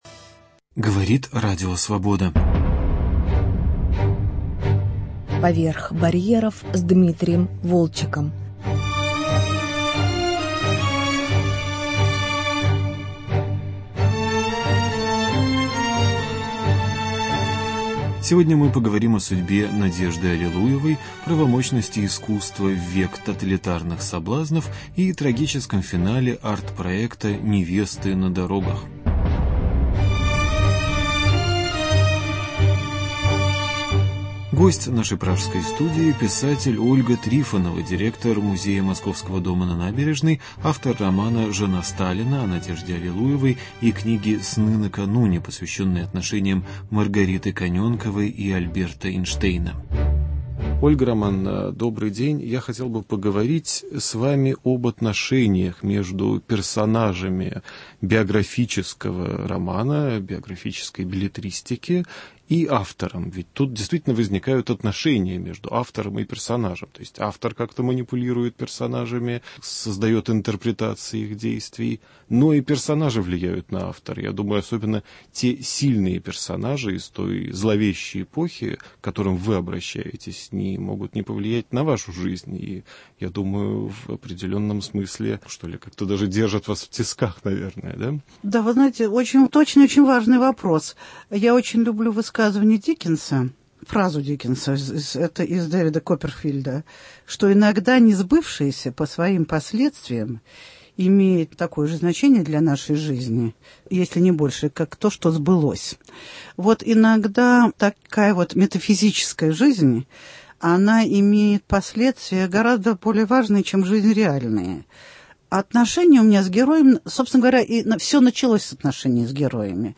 Гость программы: писатель Ольга Трифонова, автор романа "Жена Сталина"